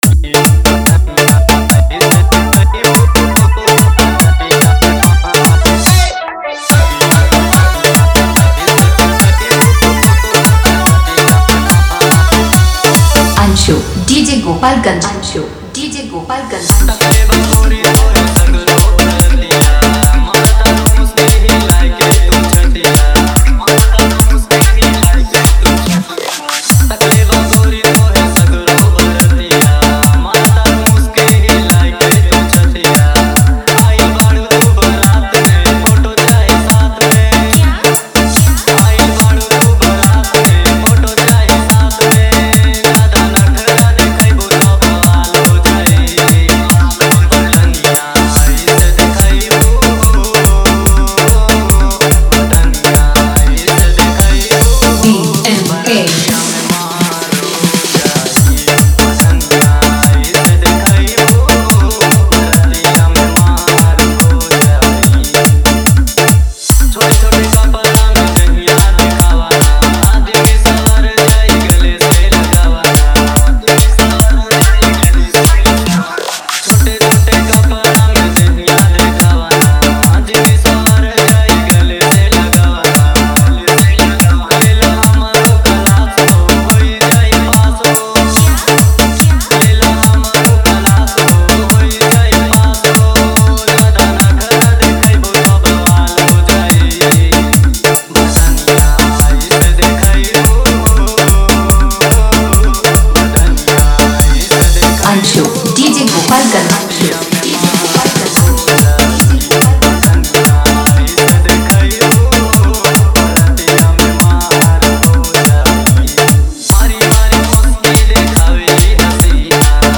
नया भोजपुरी DJ रीमिक्स सॉन्ग